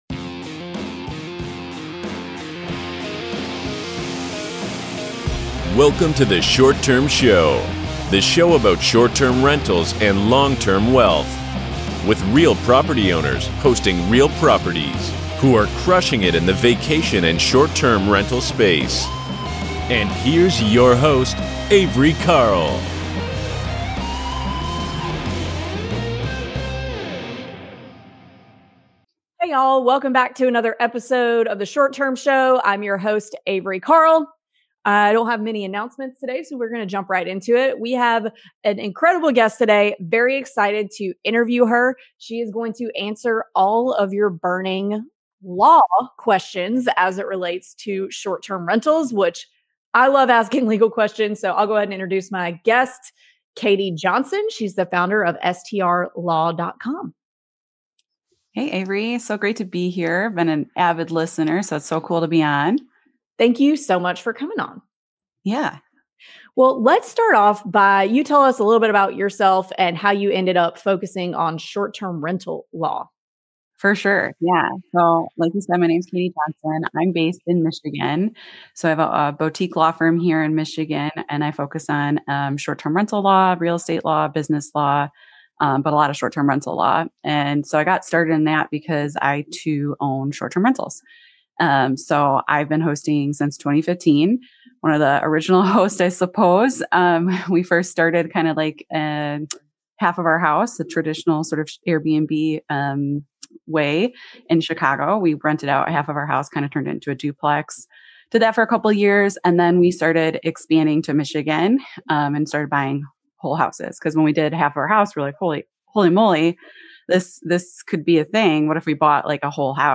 The conversation highlights how being proactive with legal strategy can save investors from costly mistakes down the road.